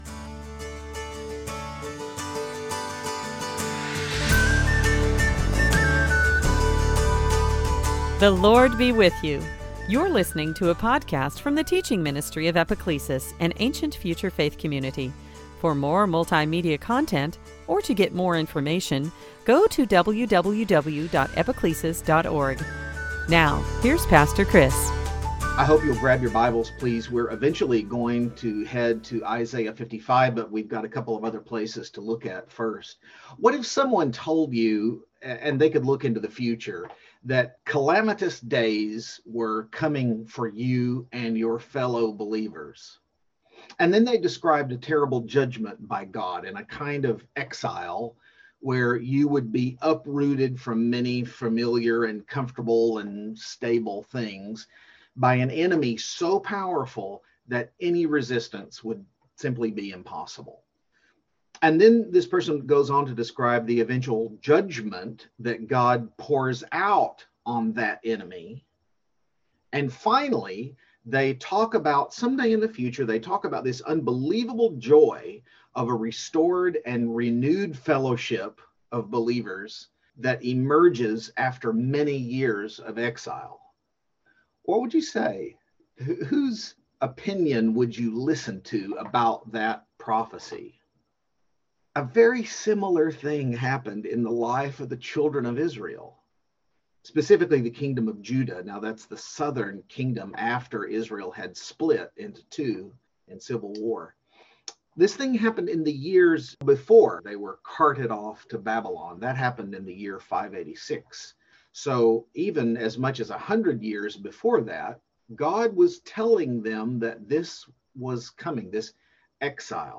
Series: Sunday Teaching